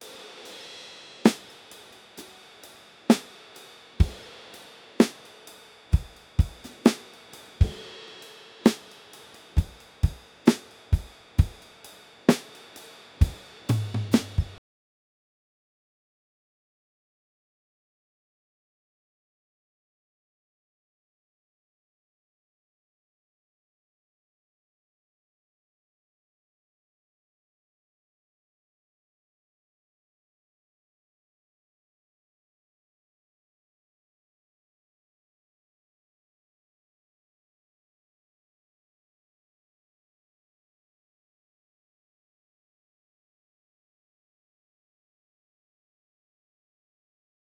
A model that transforms rhythmic audio inputs into professional drum performances by applying a multi-stage generative process, producing four unique variations per input.
• Converts diverse rhythmic sources (drums, beatboxing, body percussion) into studio-quality drum tracks